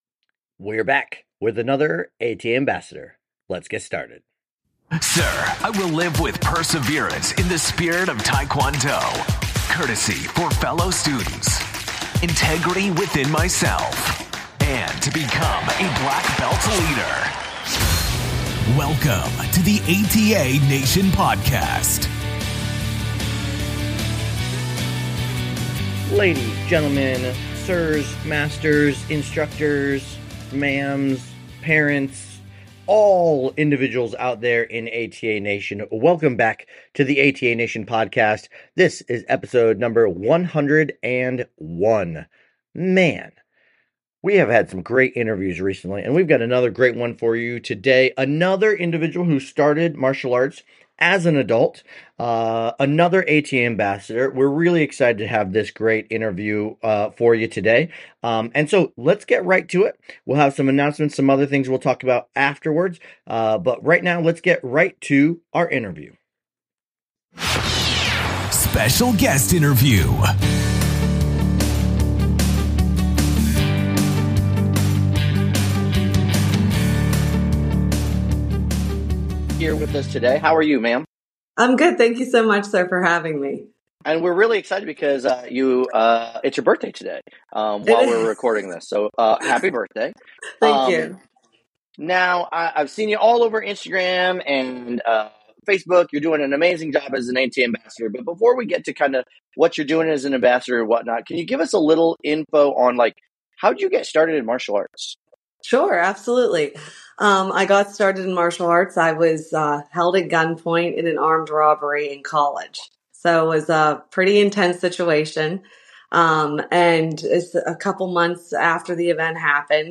We're excited to interview another adult who started martial arts in their adult years and we hope it inspires others out there as well!